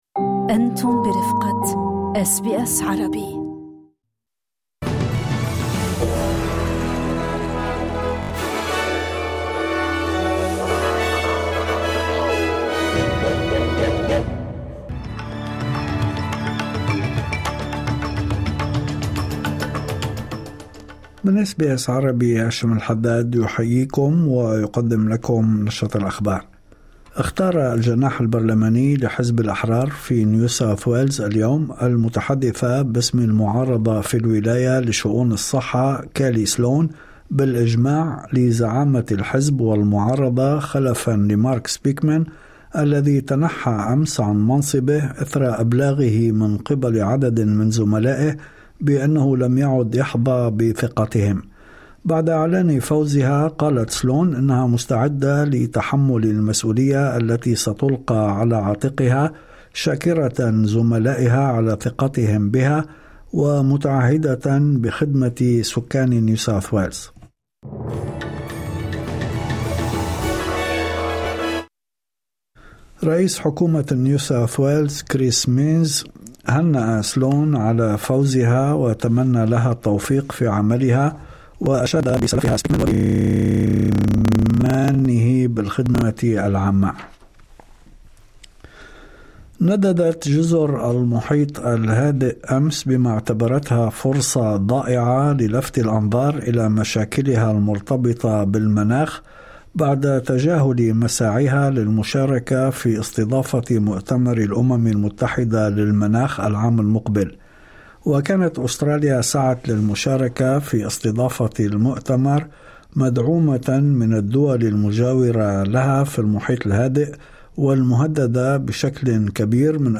نشرة أخبار الظهيرة 21/11/2025